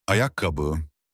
معنی و تلفظ کلمه “کفش” در ترکی استانبولی
shoes-in-turkish.mp3